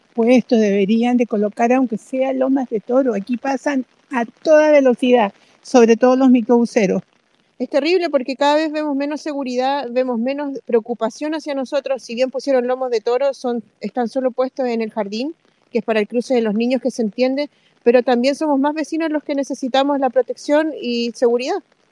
Vecinos criticaron que en el lugar exista un constante exceso de velocidad, en un tramo cuyo máximo es de 40 kilómetros por hora.